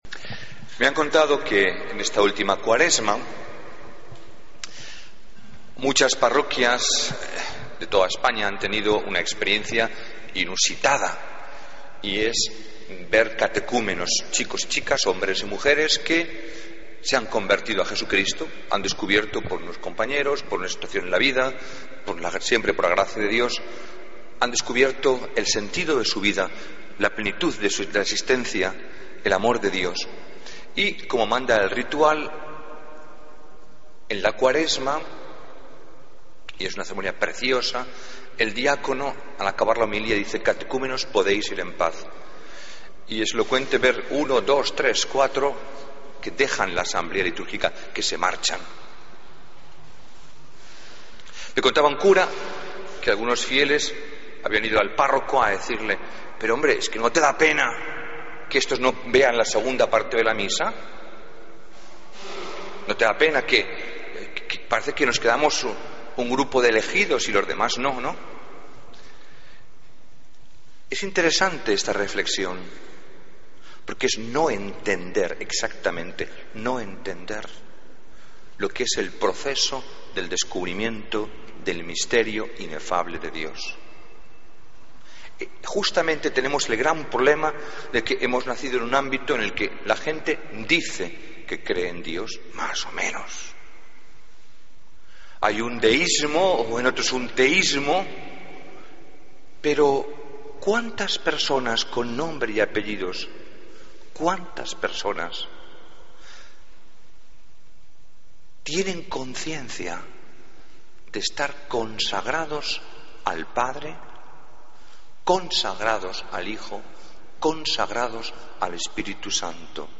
Homilía del Domingo 15 de Junio de 2014